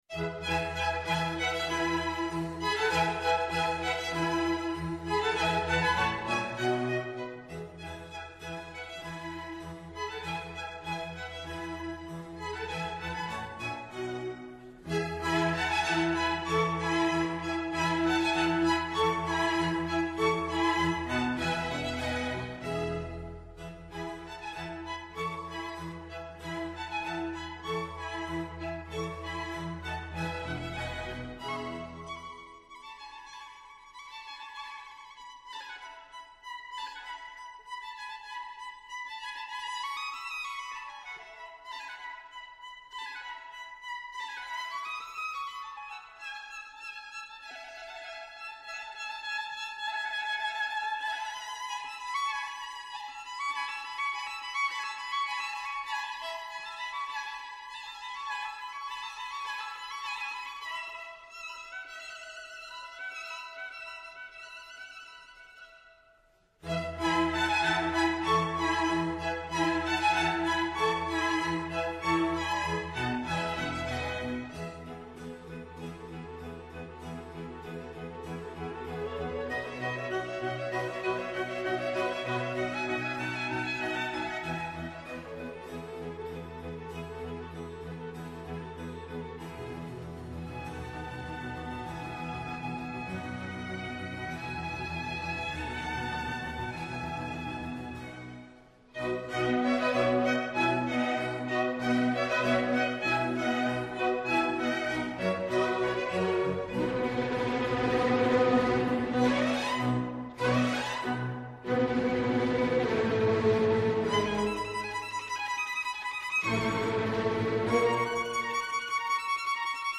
Μια συζήτηση για το αίσθημα ανασφάλειας των πολιτών με αφορμή τραγωδίες σαν το Μάτι και τώρα τα Τέμπη, την αποτυχία του κράτους να προστατεύσουν τους πολίτες και την απαίτηση των πολιτών να αποκατασταθεί αυτή η υποχρέωση.